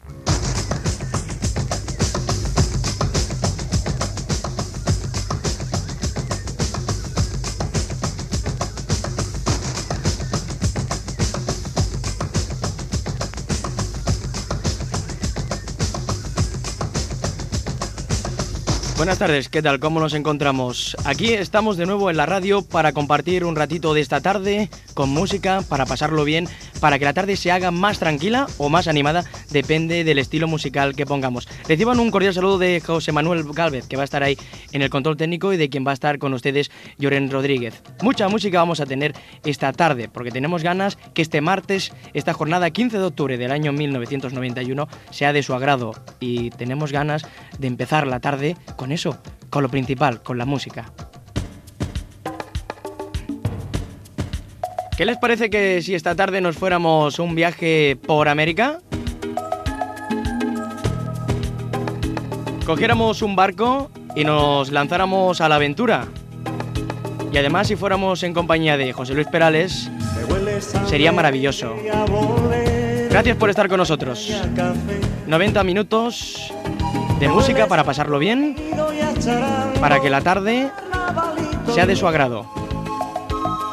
Sintonia i inici del programa amb els noms de l'equip, la data i la presentació del primer tema musical
Musical